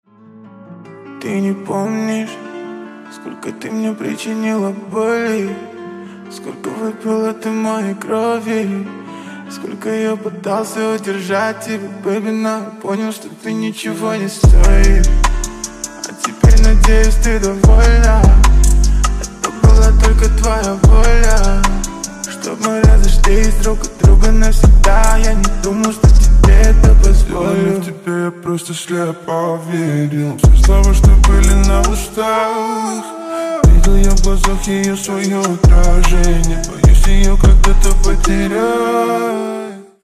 Громкие Рингтоны С Басами » # Грустные Рингтоны
Поп Рингтоны